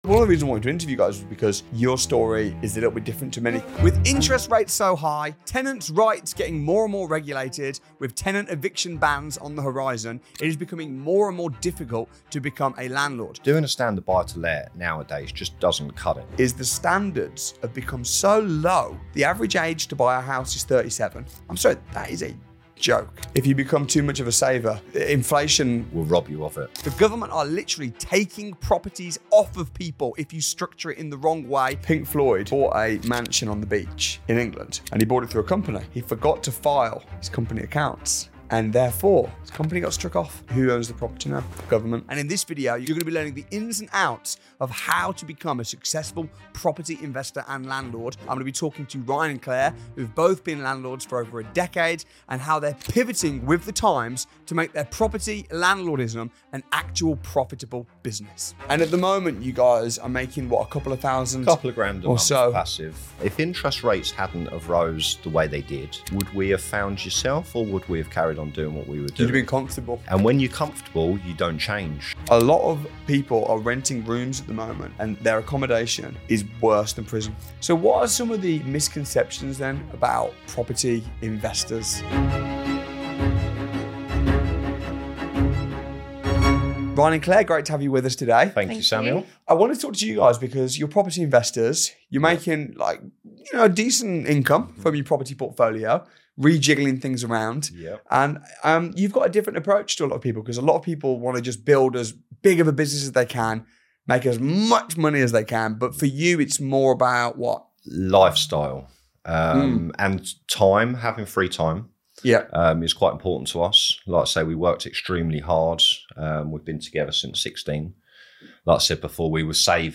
In this week’s Winners on a Wednesday, I sit down with experienced landlords